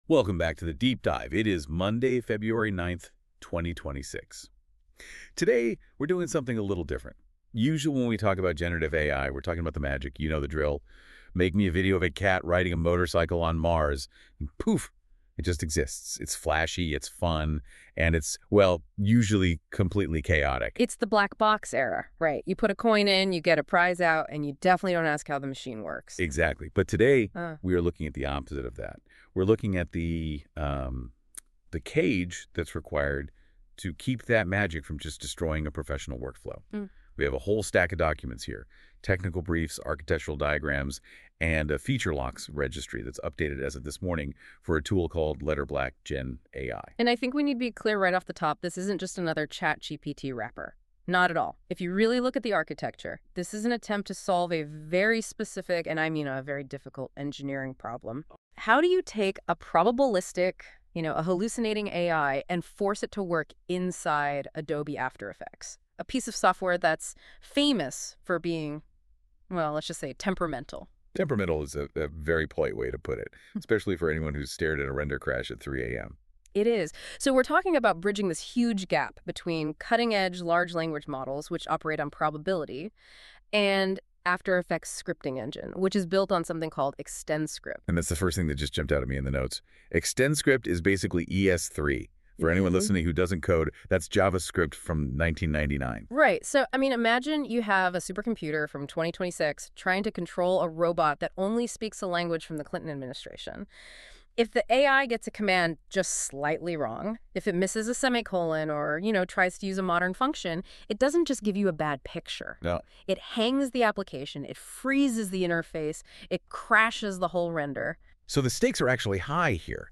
We've created a 3-minute audio walkthrough explaining what Letterblack is, how it works, and who it's built for. 3:24 minutes • Narrated overview 0:00 / 3:24